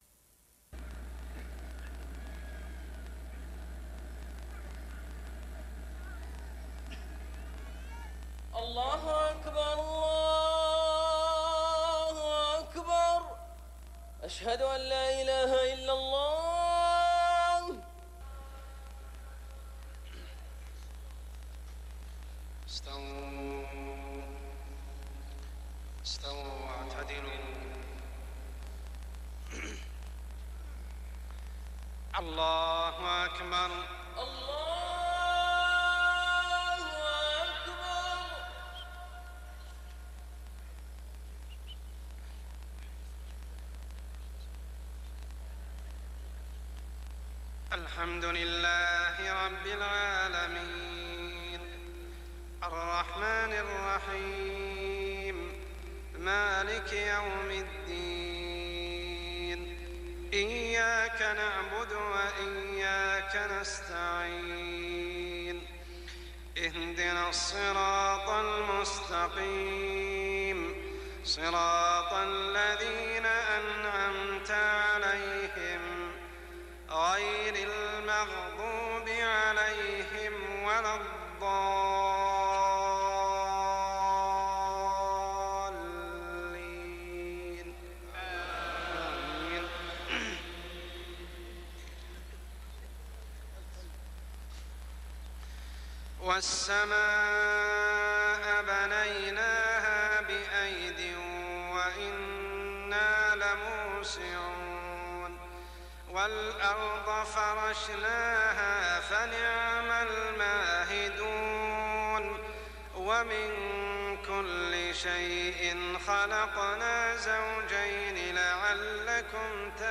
صلاة العشاء ( العام غير مذكور ) من سورة الذاريات 47-60 | Isha prayer surah Adh-Dhaariyat > 1420 🕋 > الفروض - تلاوات الحرمين